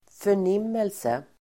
Uttal: [för_n'im:else]